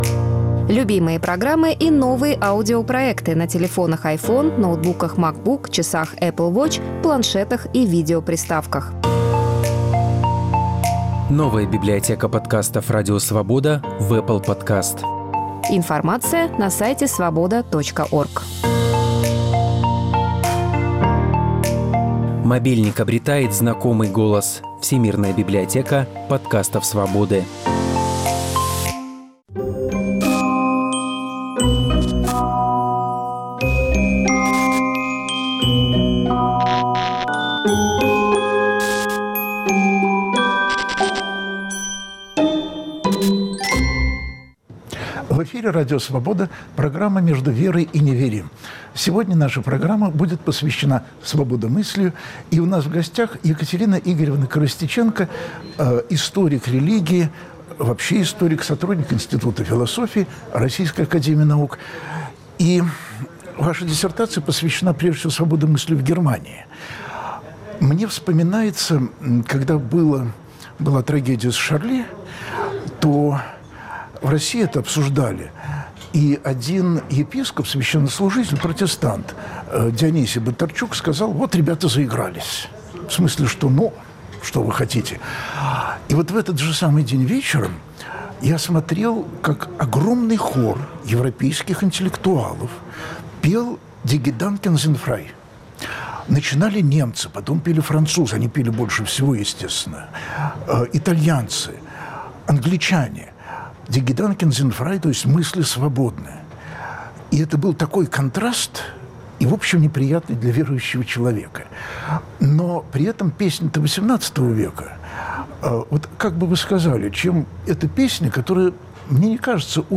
Почему свободомыслие - антирелигиозное понятие? Об этом в программе «Между верой и неверием» разговор с религиоведом